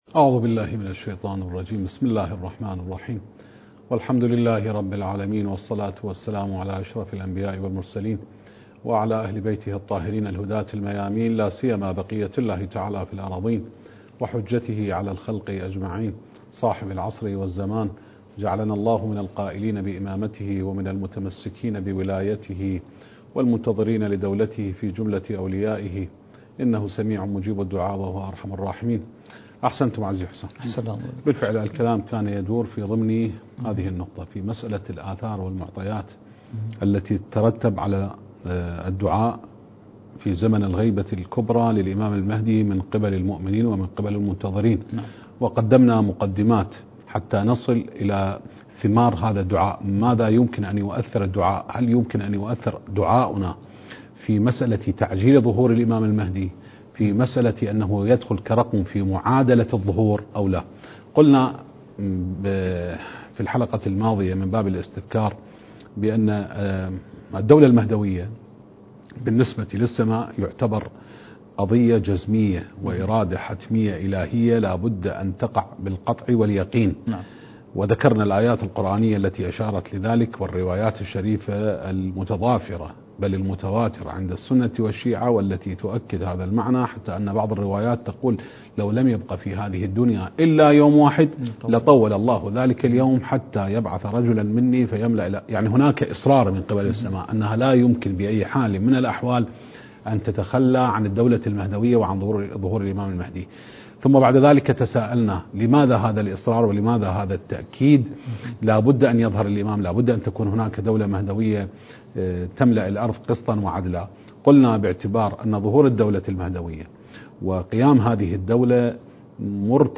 المكان: موكب النجف الأشرف/ قم المقدسة التاريخ: 1444 للهجرة